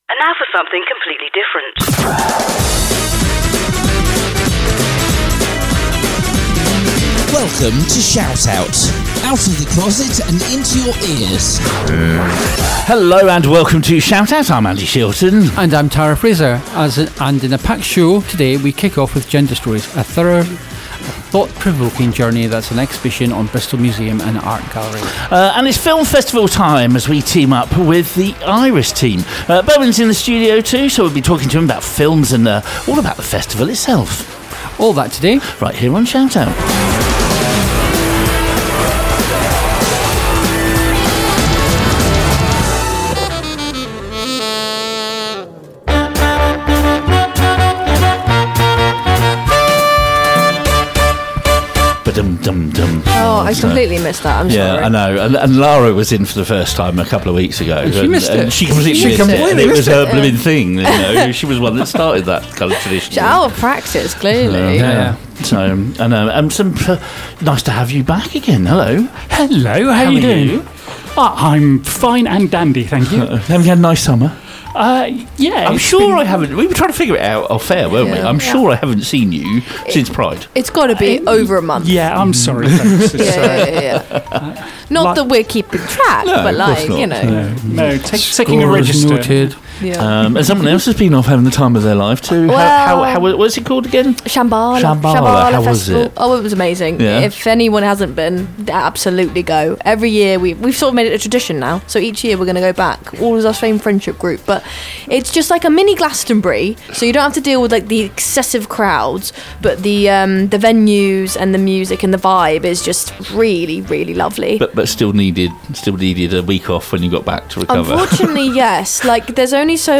Tune in for interviews, reflections, and the politics of showing up.